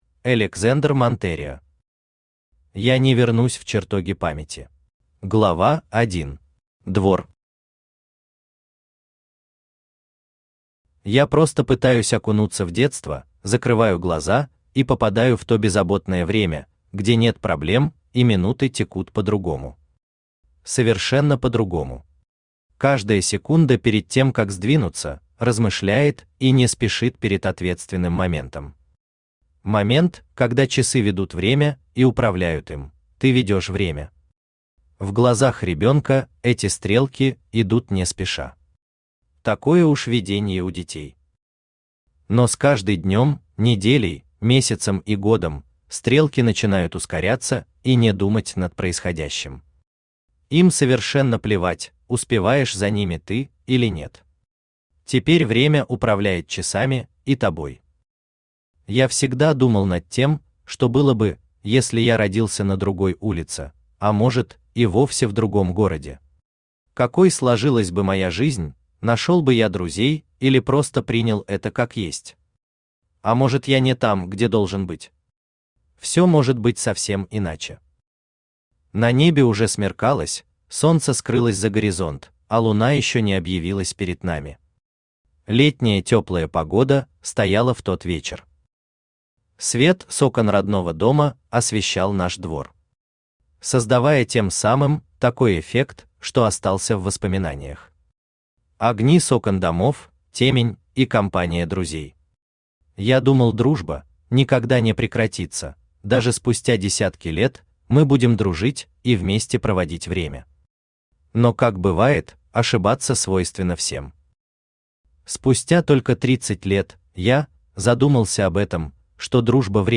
Aудиокнига Я не вернусь в чертоги памяти Автор Alexander Monterio Читает аудиокнигу Авточтец ЛитРес.